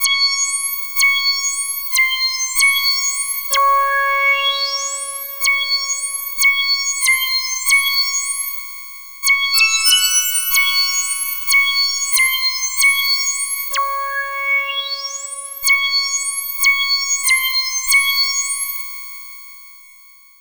Ridin_ Dubs - Sweep FX.wav